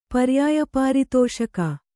paryāya pāritōṣaka